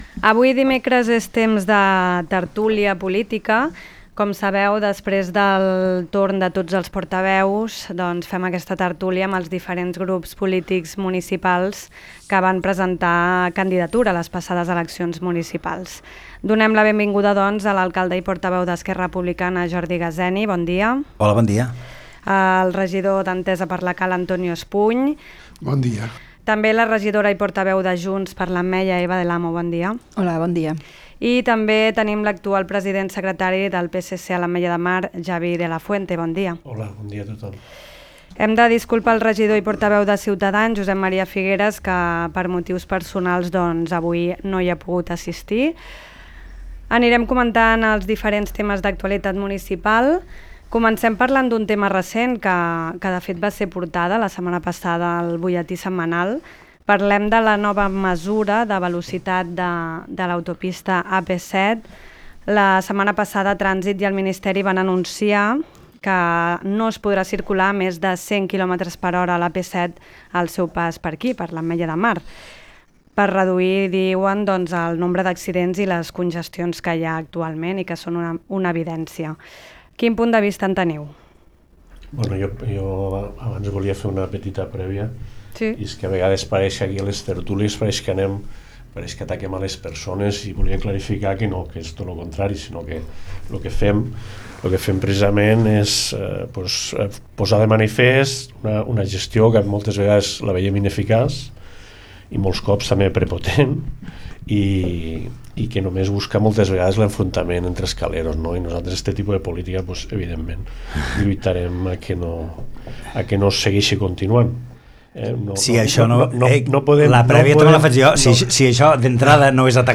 Navegació d'entrades La tertúlia política Releated Posts Butlletí Informatiu 17/04/2026 La Cala es juga la categoria al camp del Perelló￼ Doble cita cultural de l’Ateneu Calero al Centre d’Interpretació de la Pesca